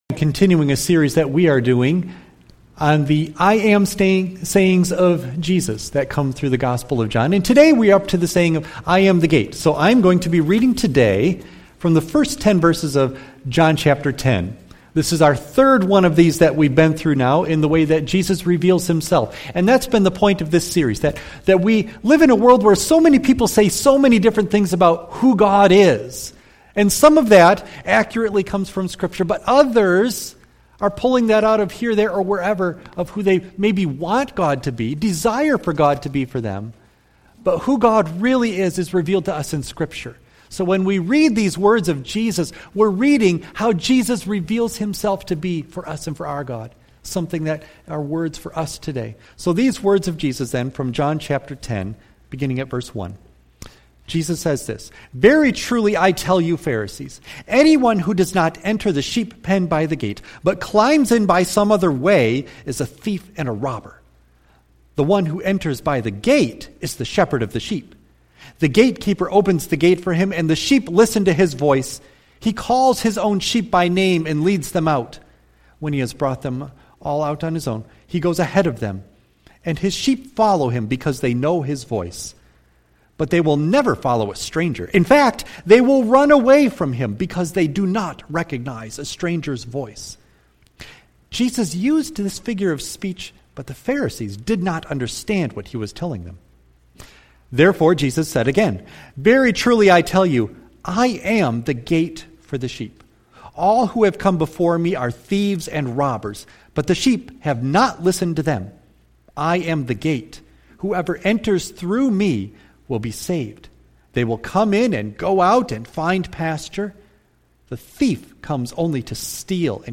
John 10:1-10 Service Type: Sunday AM Bible Text